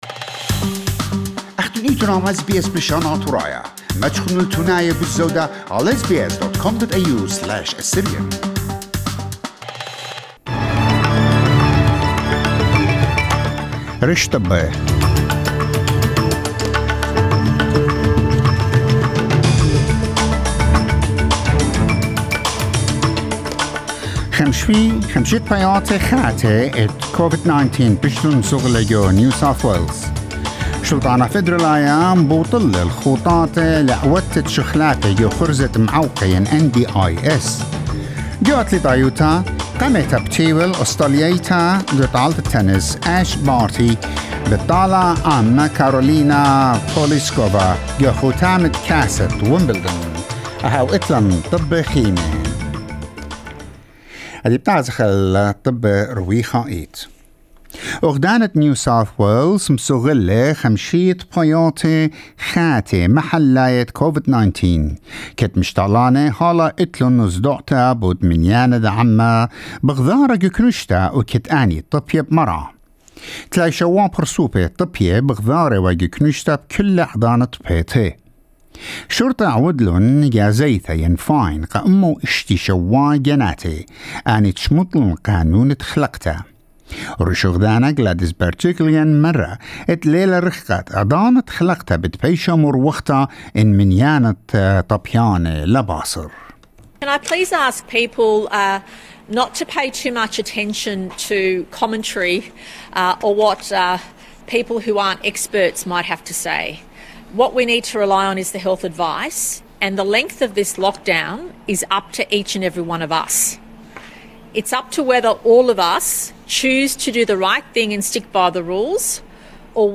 SBS News in Assyrian Saturday 10 July 2021